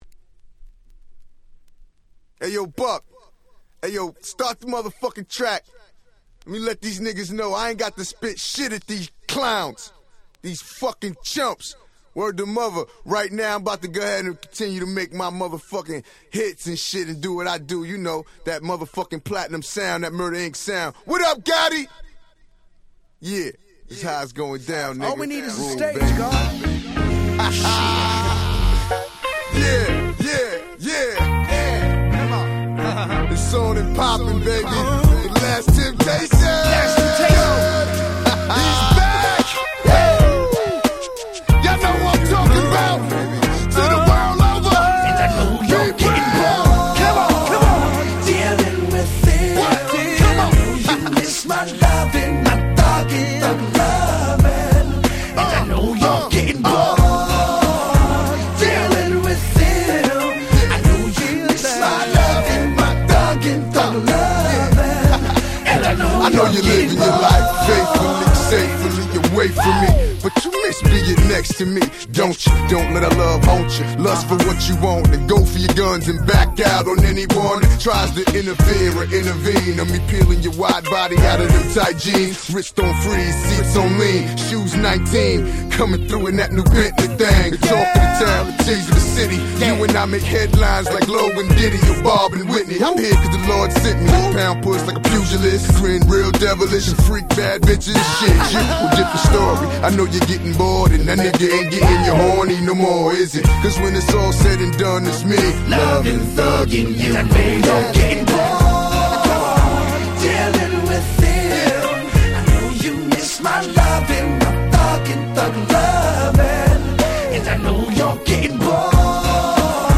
02' Smash Hit Hip Hop !!